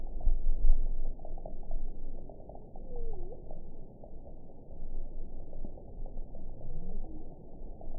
event 922137 date 12/27/24 time 05:43:29 GMT (5 months, 3 weeks ago) score 5.27 location TSS-AB06 detected by nrw target species NRW annotations +NRW Spectrogram: Frequency (kHz) vs. Time (s) audio not available .wav